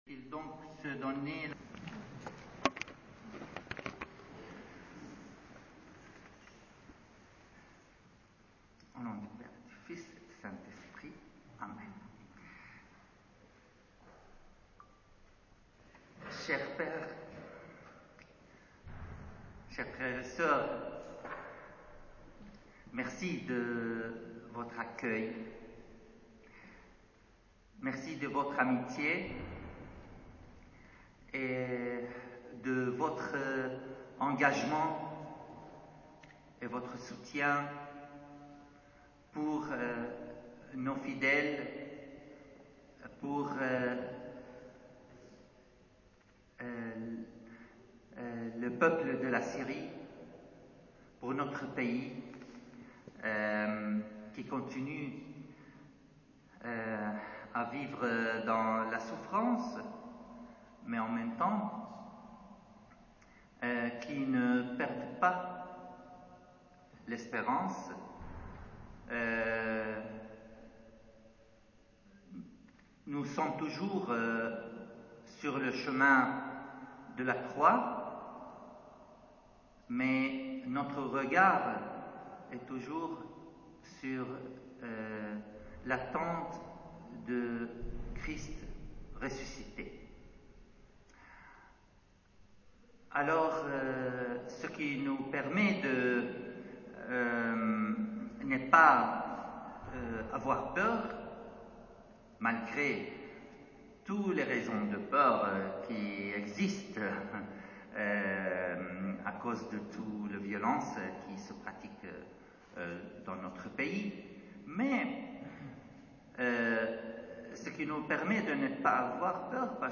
Homélie
Vigile Pascale